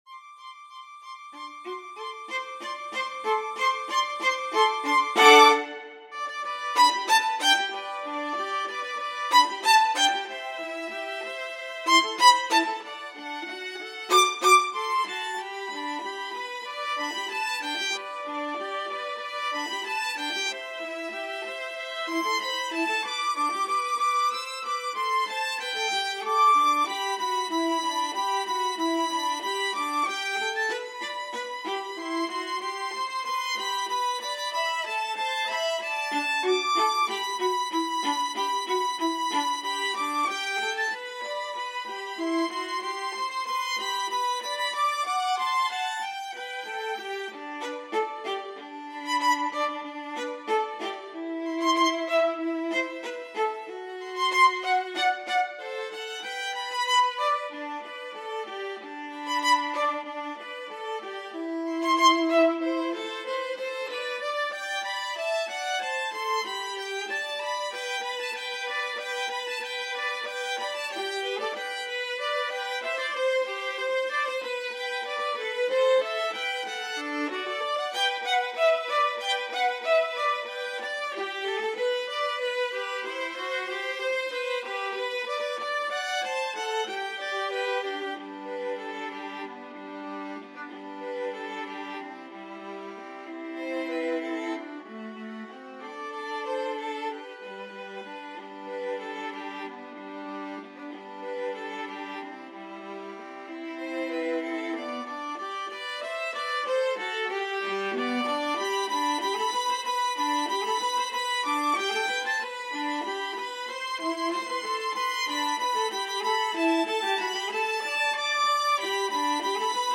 Duet: Violin & Viola Genre: Christmas & Other Holiday